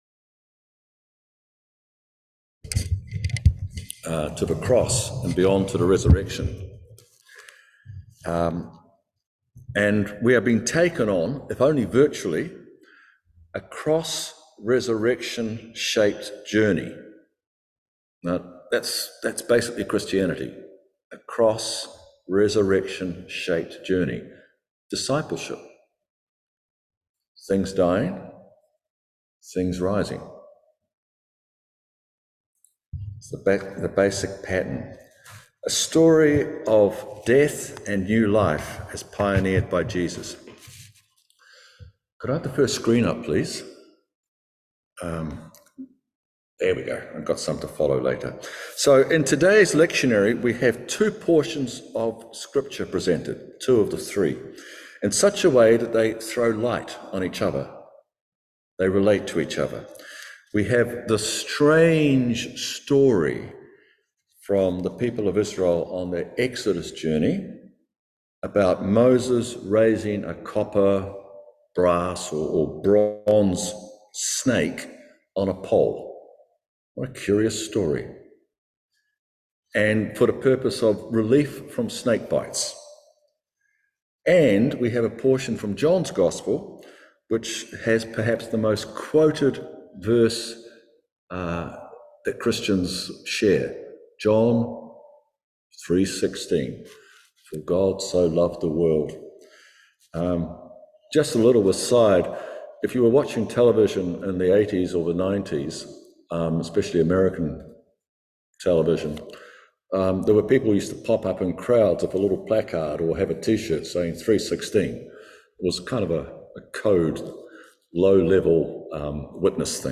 John 3:14-21 Service Type: Morning Worship Looking to Jesus